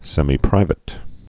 (sĕmē-prīvĭt, sĕmī-)